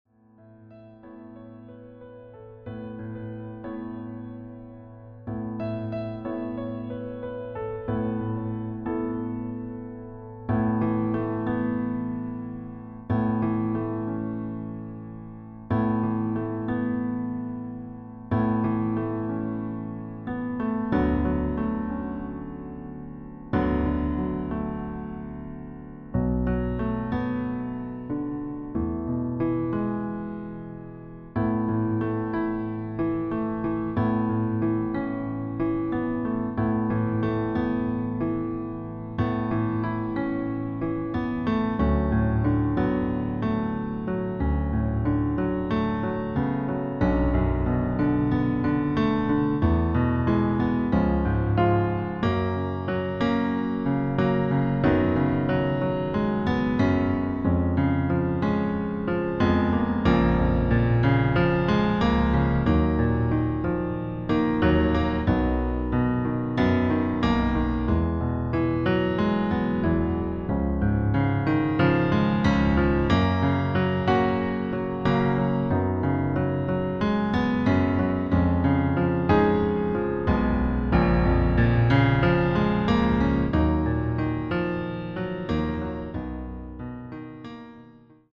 Demo in A Dur:
• Das Instrumental beinhaltet NICHT die Leadstimme
Klavier / Piano